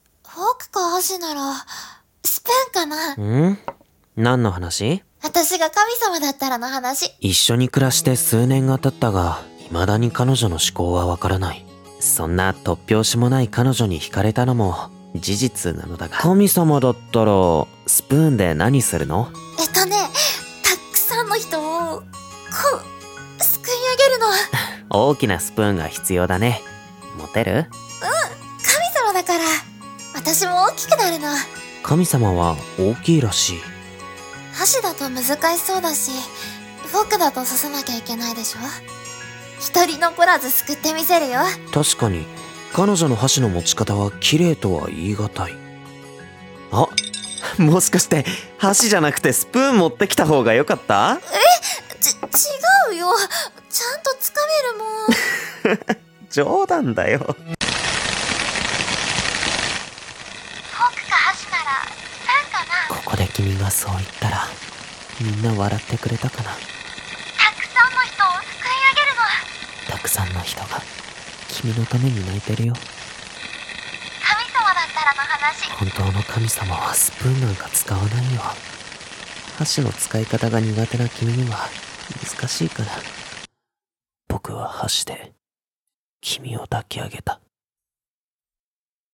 【二人声劇】箸、わたし